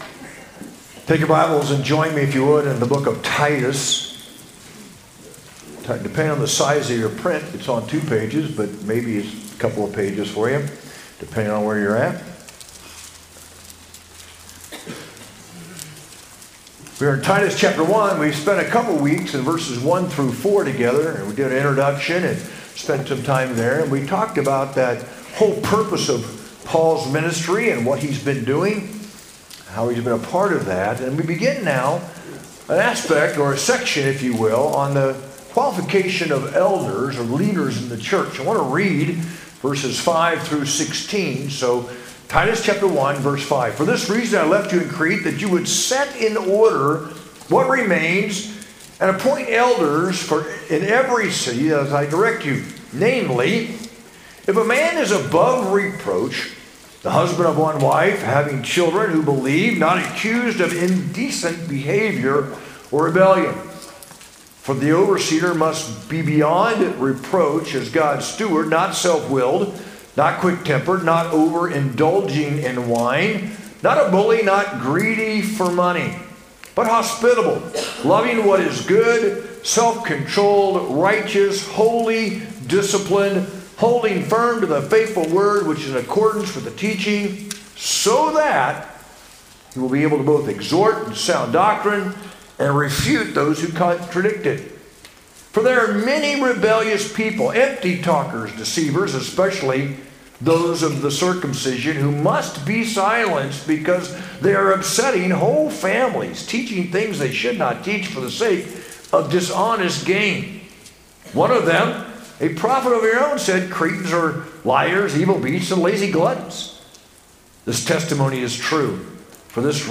sermon-3-16-25.mp3